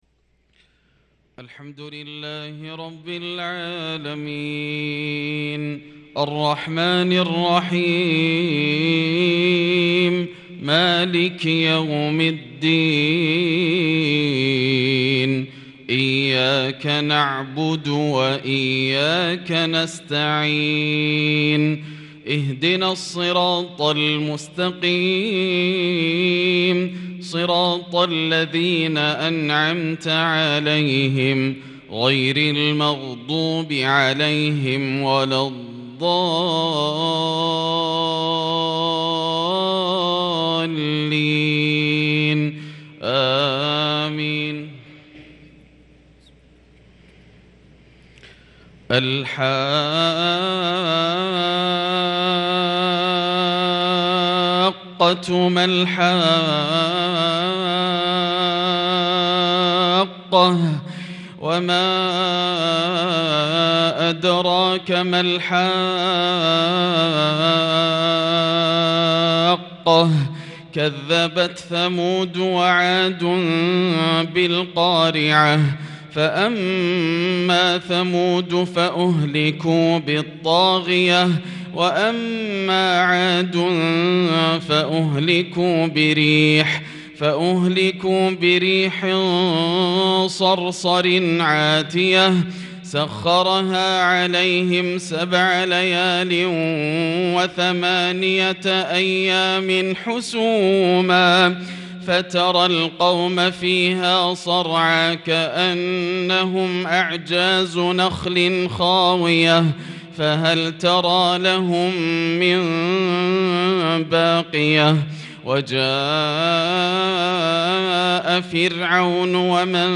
صلاة الفجر للقارئ ياسر الدوسري 24 ذو القعدة 1443 هـ
تِلَاوَات الْحَرَمَيْن .